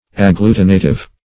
Agglutinative \Ag*glu"ti*na*tive\, a. [Cf. F. agglutinatif.]